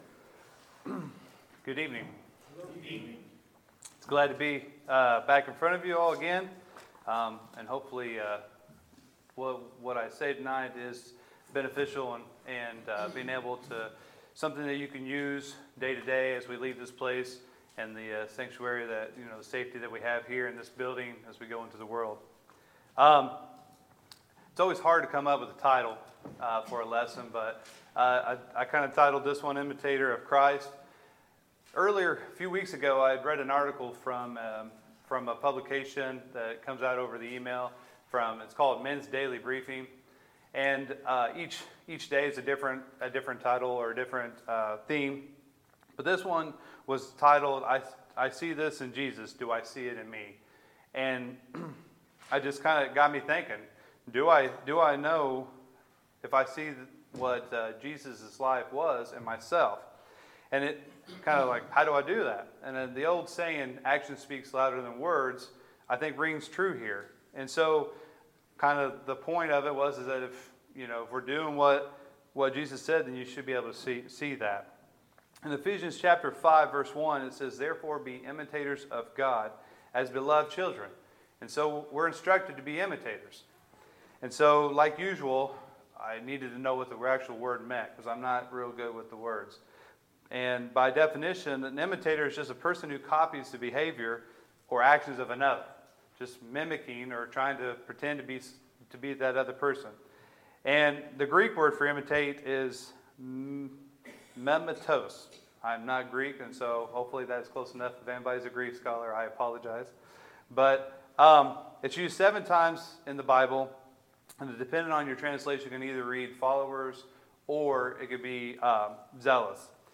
Sermons, April 28, 2019